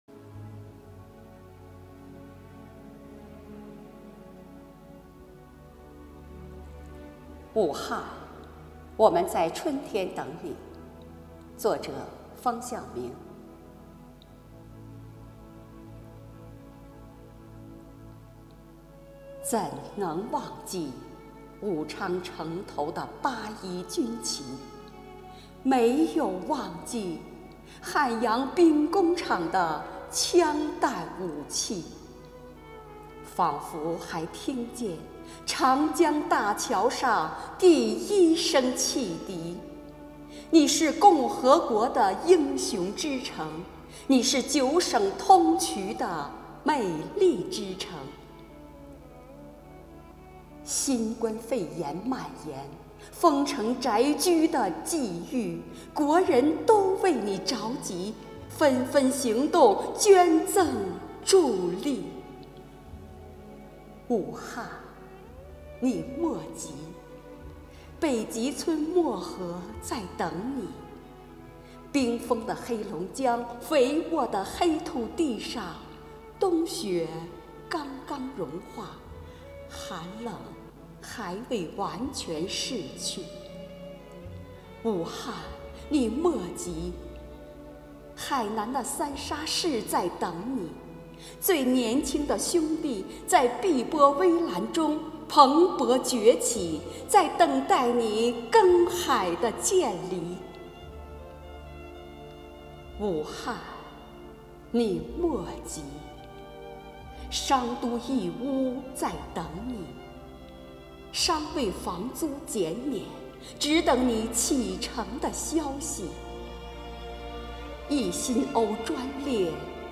武汉  我们在春天等你（朗诵诗）